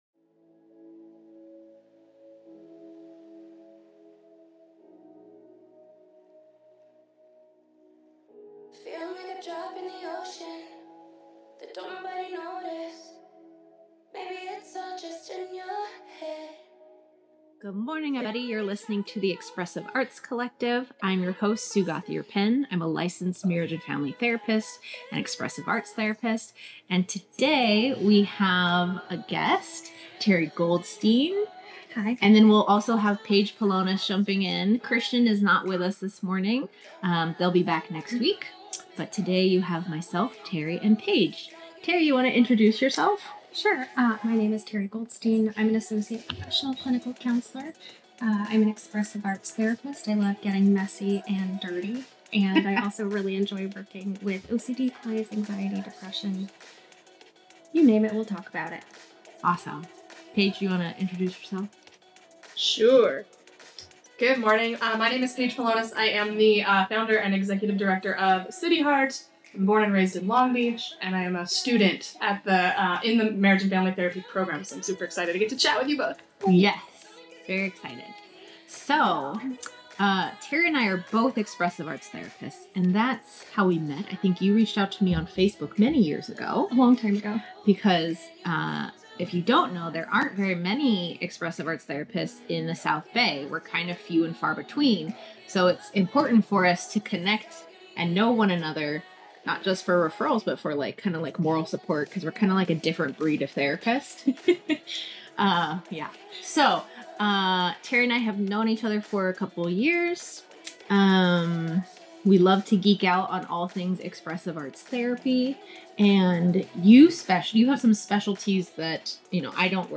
Pieces of the live broadcast have been edited and/or removed from this recording.